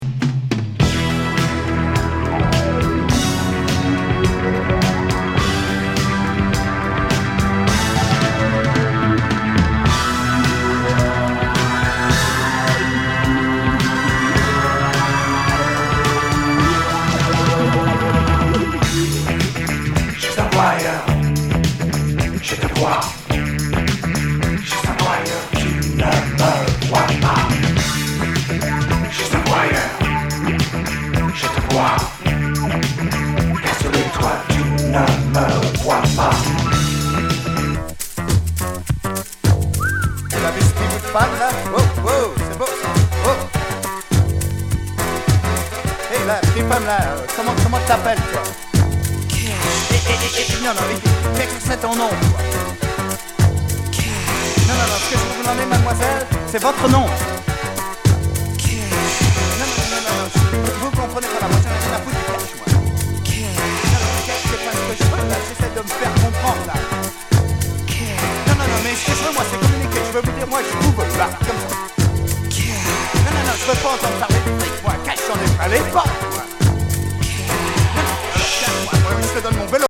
ストレンジ・ファンク・グルーブ
シンセ・サイケ